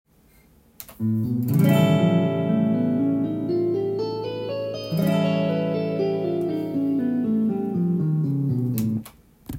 おしゃれな曲で使われる変わった響きがするスケール
Aホールトーンスケール